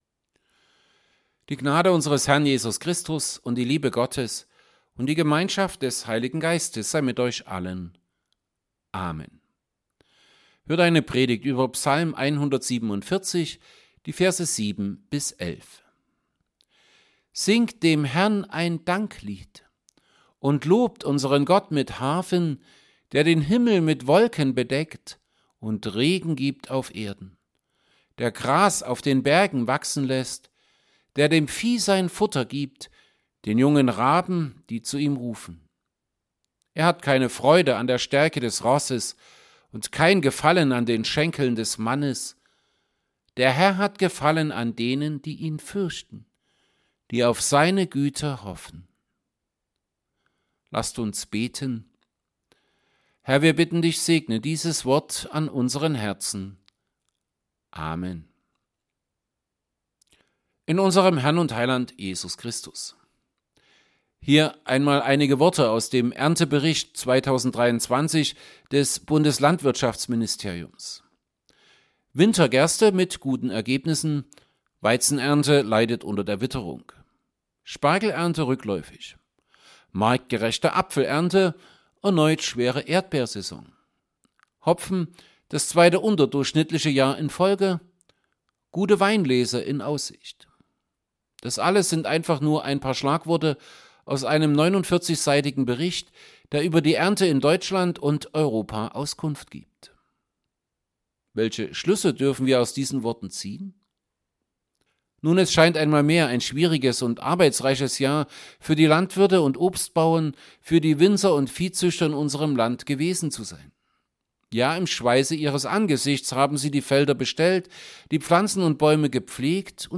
Altes Testament Passage: Psalm 147:7-11 Gottesdienst